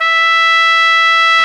Index of /90_sSampleCDs/Roland L-CDX-03 Disk 2/BRS_Piccolo Tpt/BRS_Picc.Tp 1